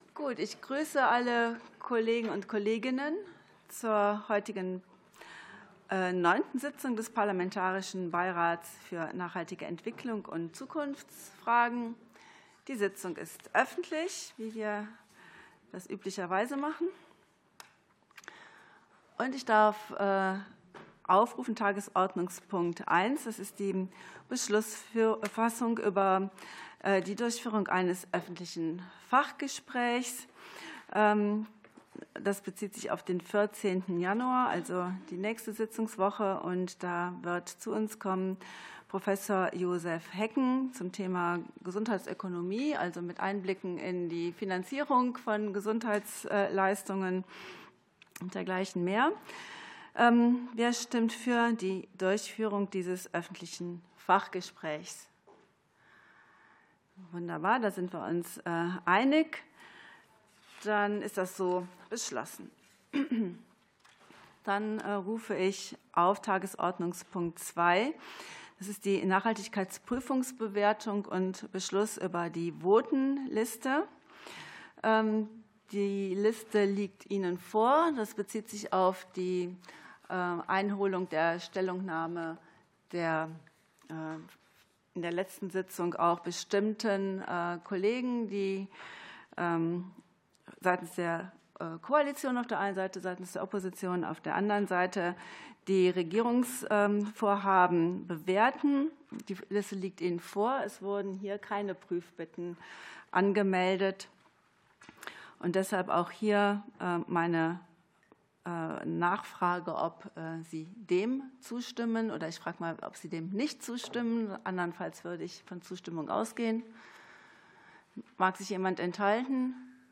Auswirkungen des Klimawandels auf die menschliche Gesundheit ~ Ausschusssitzungen - Audio Podcasts Podcast